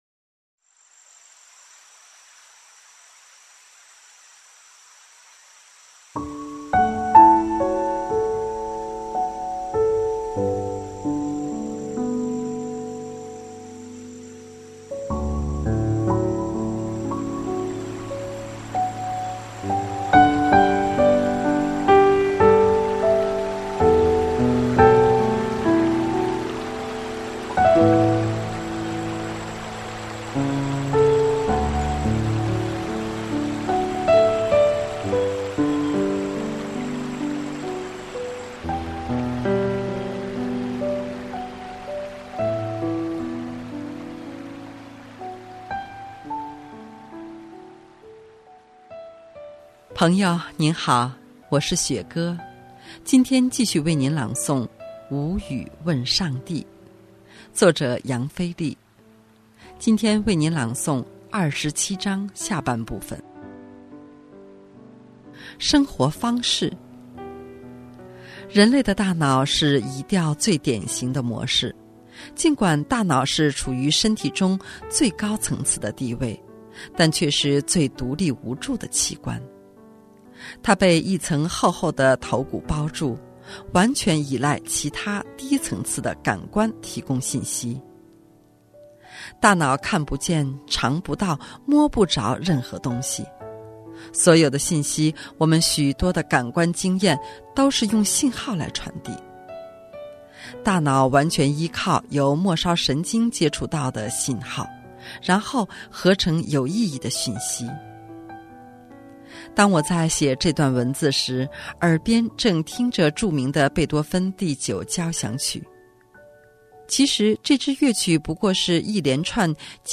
今天继续为你朗读《无语问上帝》。